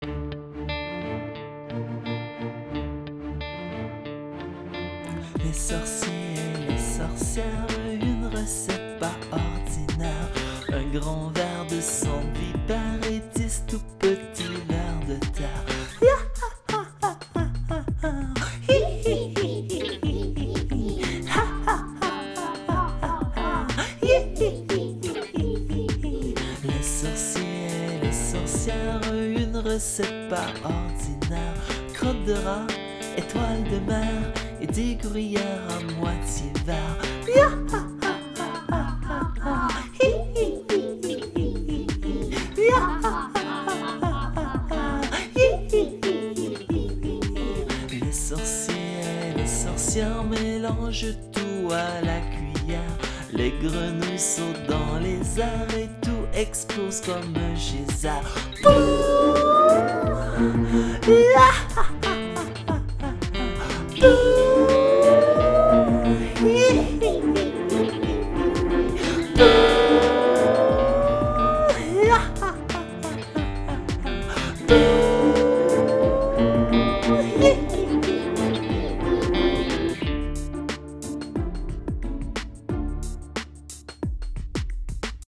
La version rapide peut être difficile pour les plus jeunes.